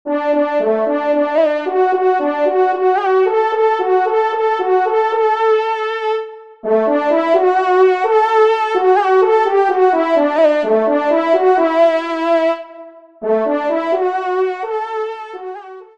Genre : Musique Religieuse pour Quatre Trompes ou Cors
Pupitre 1°Trompe